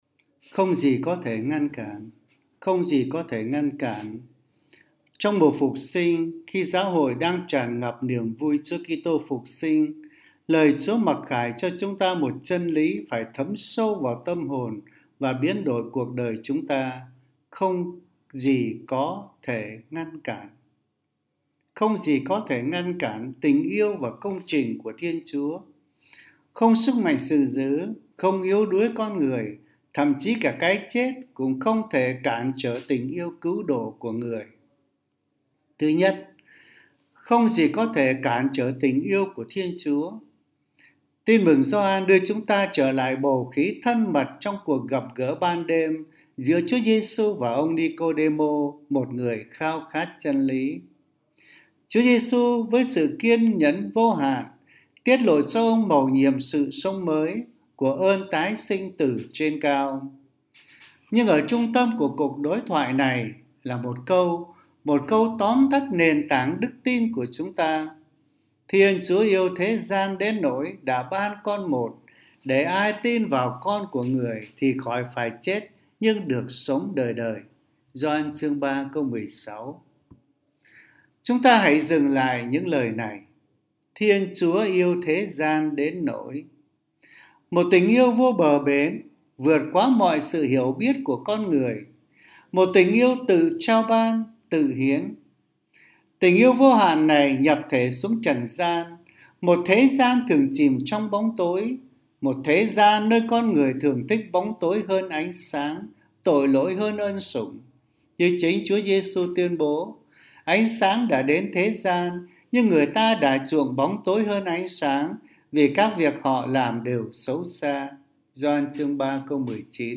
Suy niệm hằng ngày
Nữ miền Nam 🎙 Nam miền Nam